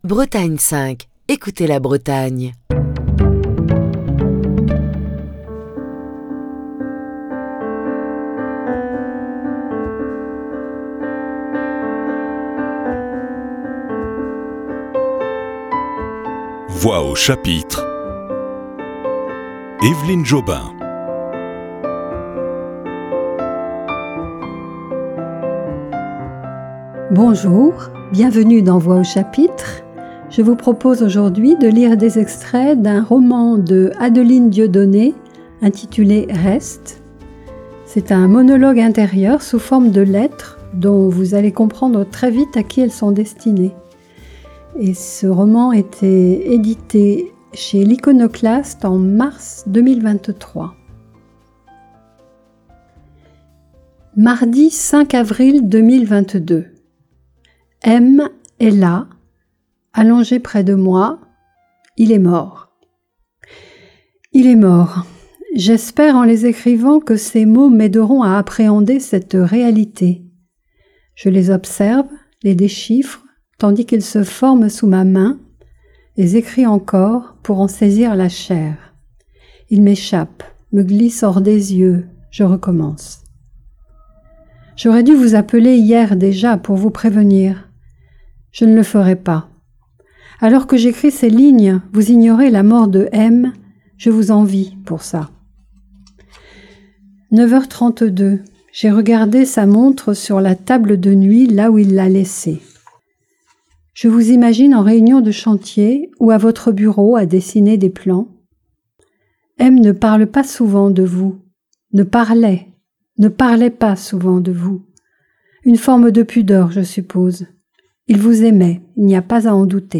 la lecture de quelques extraits d'un roman